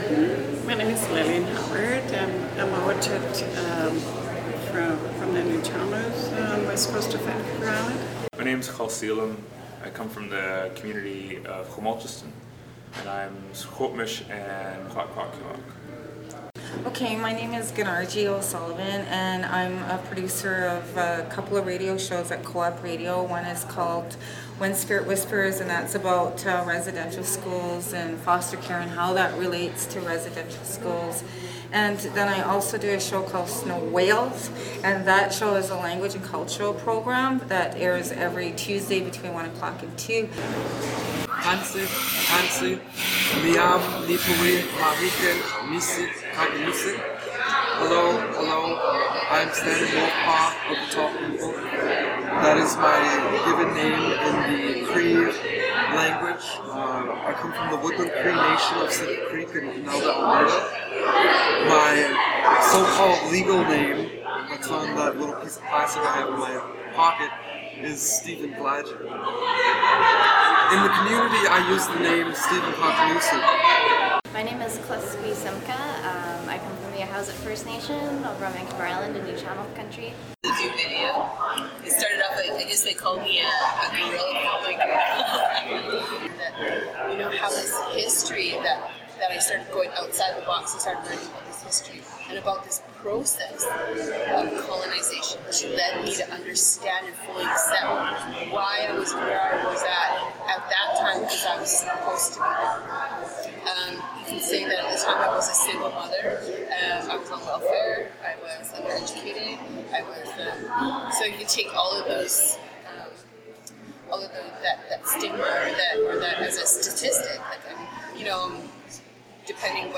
Organizers @ INM conference talk about the struggle
Clips taken from full-length interviews with organizers at the 2013 INM Organizers' Conference.